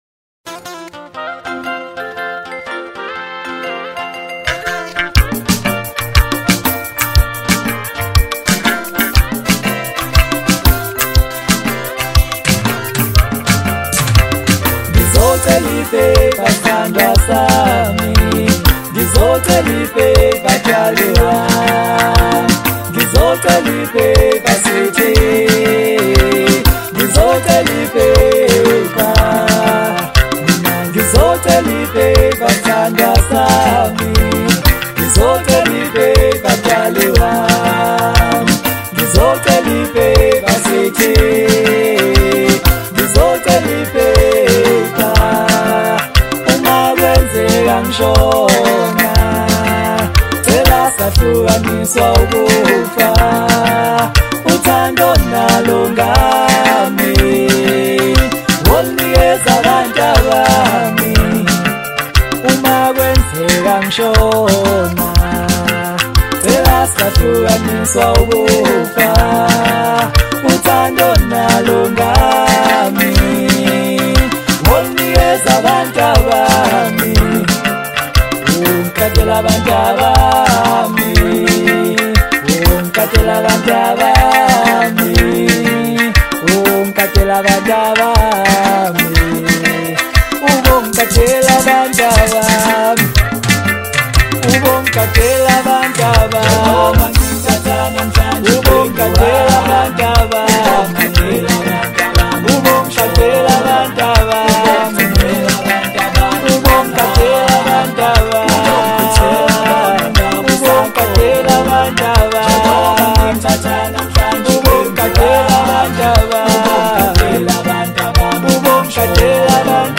Home » Hip Hop » Latest Mix » Maskandi
Talented vocalist